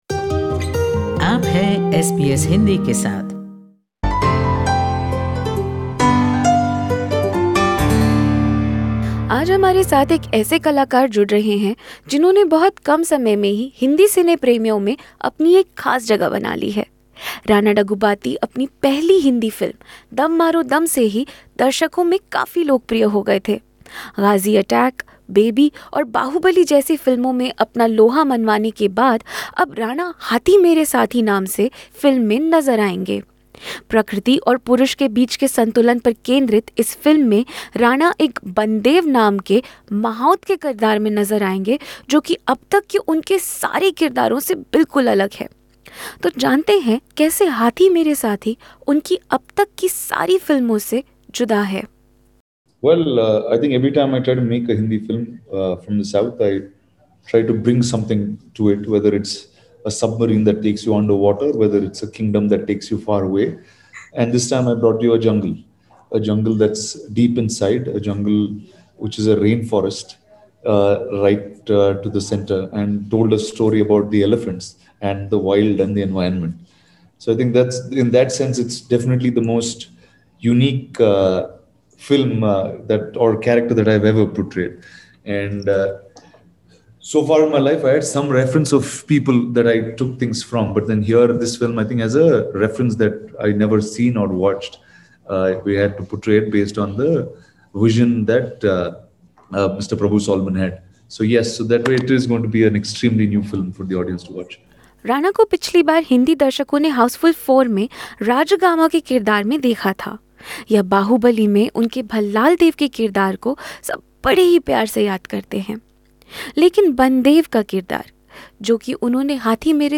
In an exclusive interview with SBS Hindi, Rana Daggubati talks about training with 30 elephants for his latest film Haathi Mere Saathi and why he has never been to Australia.